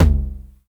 TOM XC.TOM02.wav